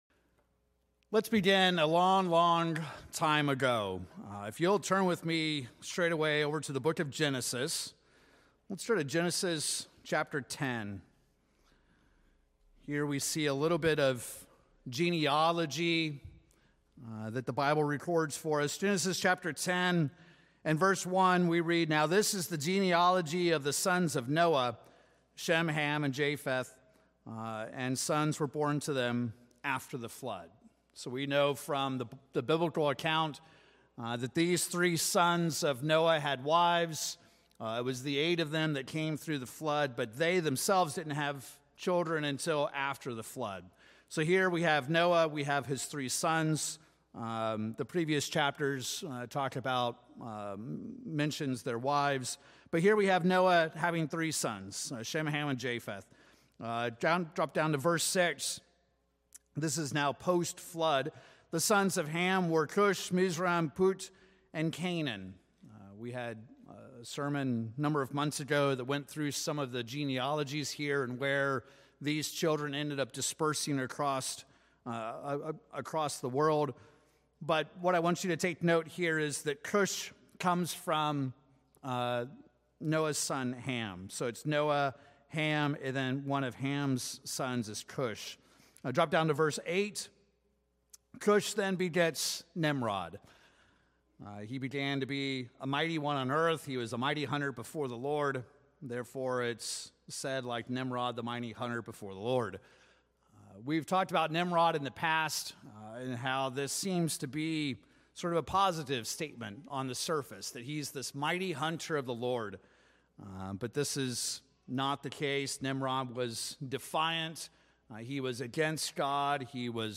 This sermon will compare and contrast God's true church with Satan's false church. We will see how God's true church is not a sect, but rather the original.